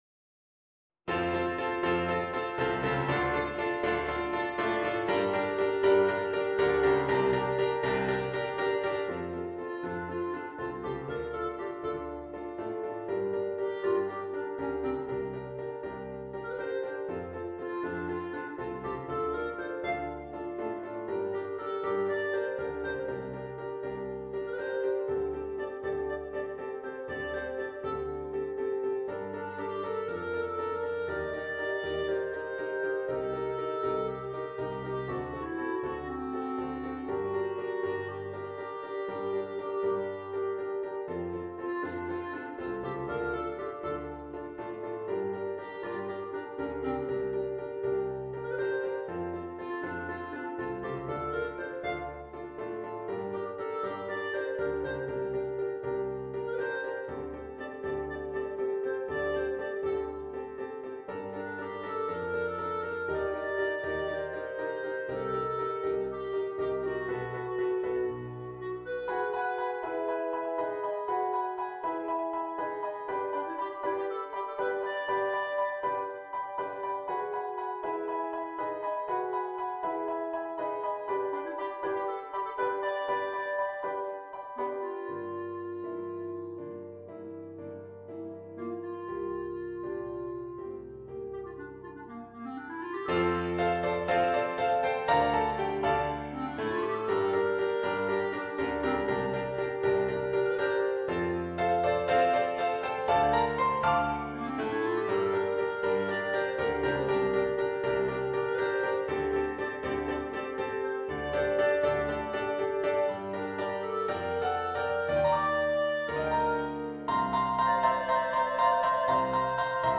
• Reference mp3 Clarinet version